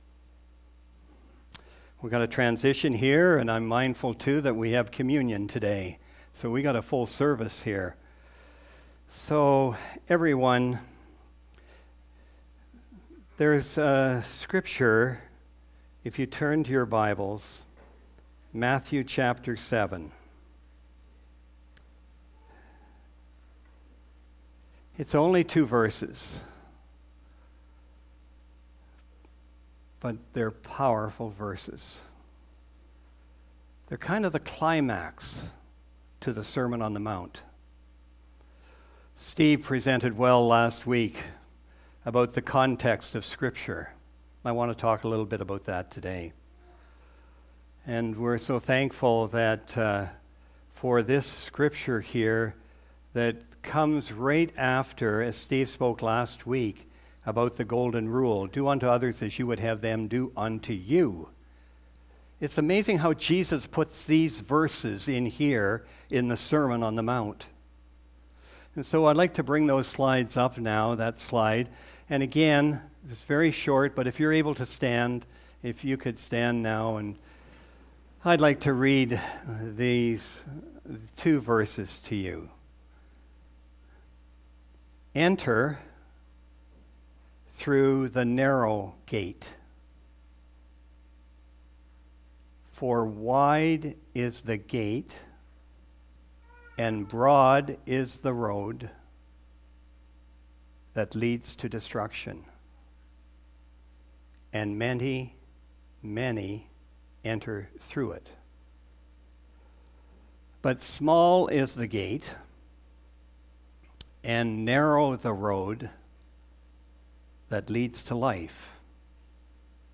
Sermon Audio and Video Tow Paths ...